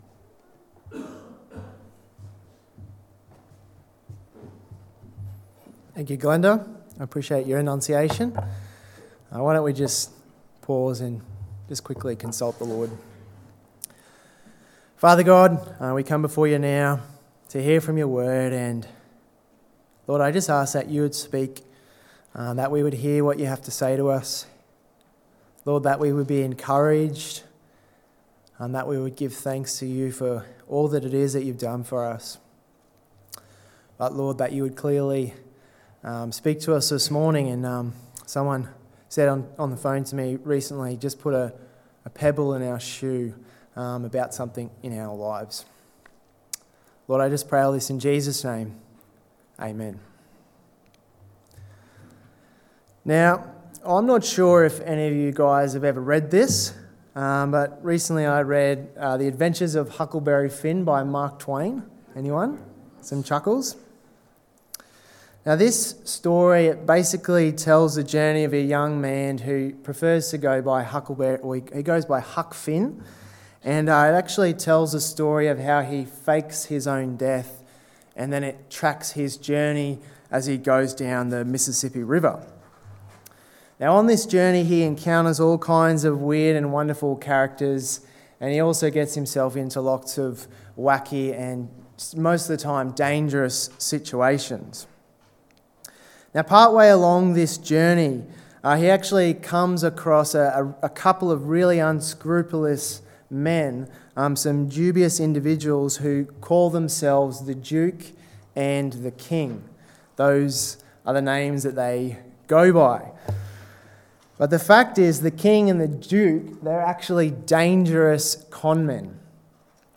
Sermons | Tenthill Baptist Church